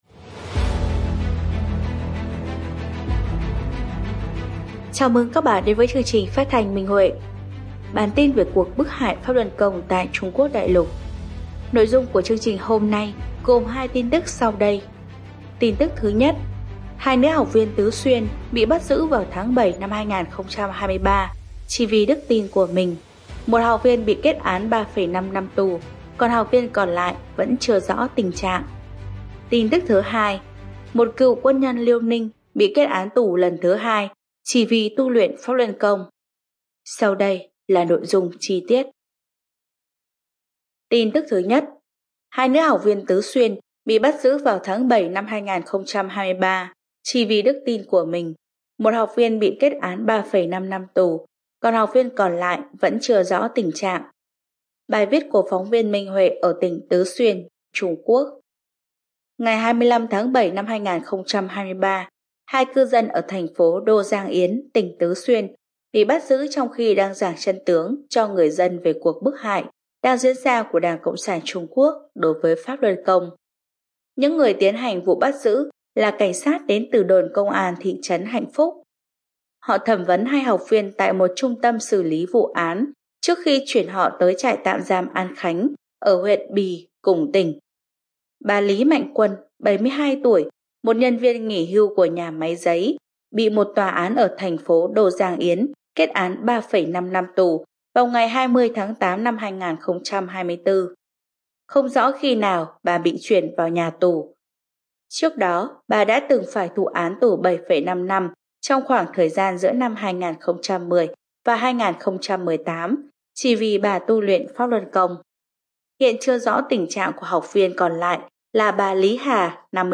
Chương trình phát thanh số 162: Tin tức Pháp Luân Đại Pháp tại Đại Lục – Ngày 10/12/2024